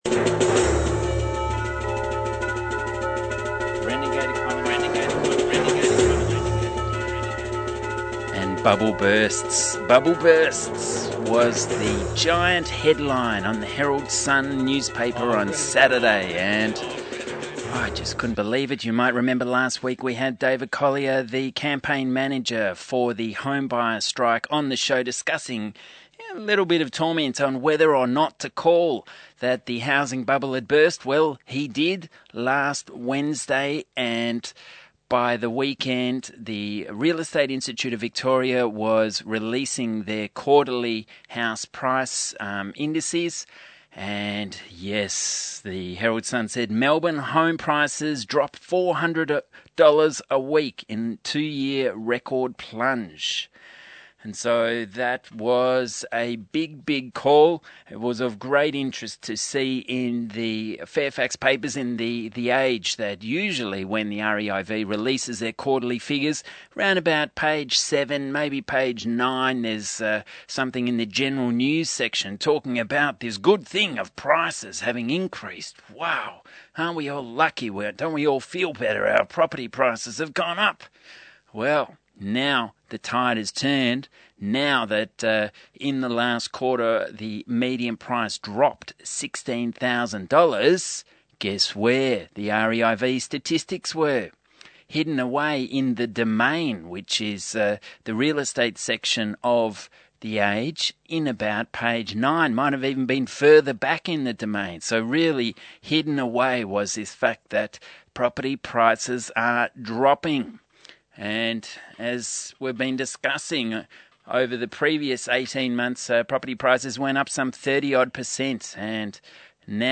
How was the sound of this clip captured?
As broadcast on the almighty 3CR, Wed 20th April, 2011.